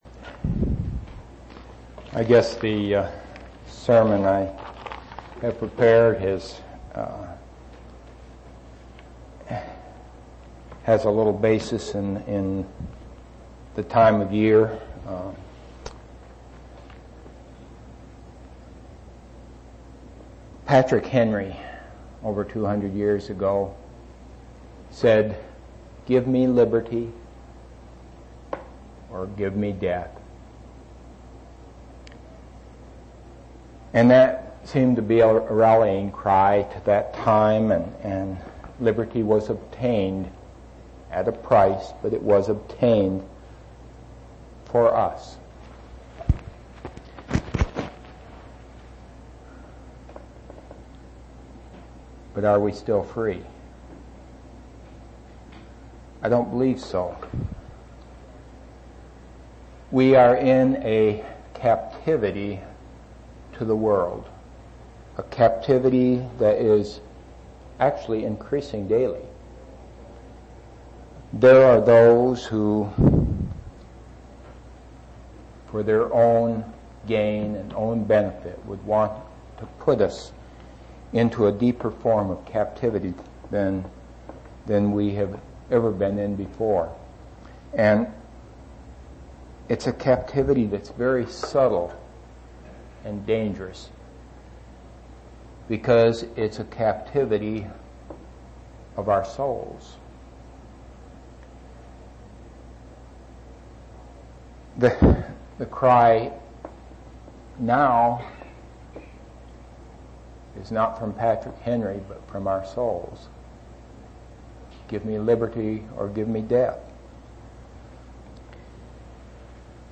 6/26/1994 Location: East Independence Local Event